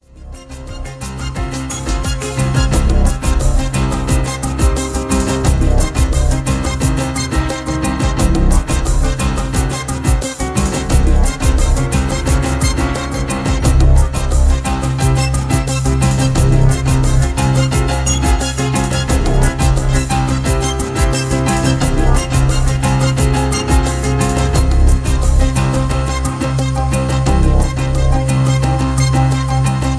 Marching Soldiers.